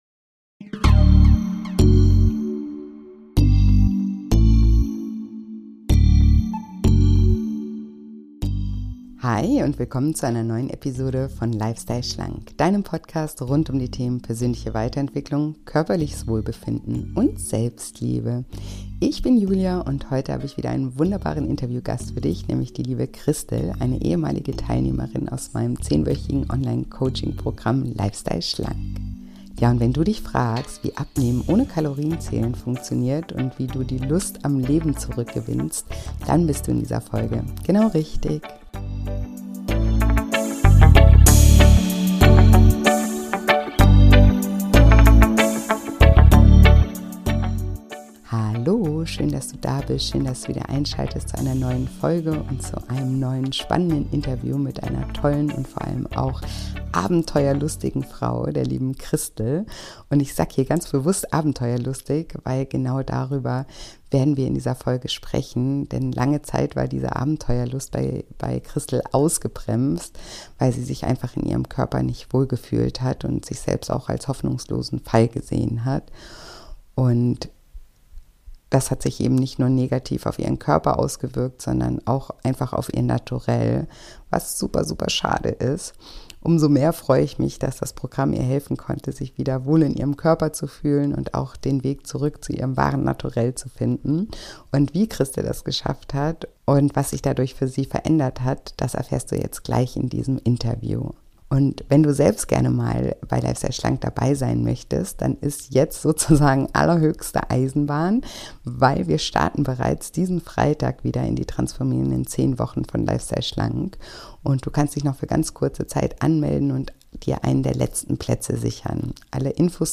Im Interview erzählt sie, wie sie es schaffte, plötzlich ohne Kalorienzählen ganz leicht abzunehmen und wie das dazu führte, dass sie sich so frei und lebendig wie nie zuvor fühlte und ihre Lust am Leben zurückgewann.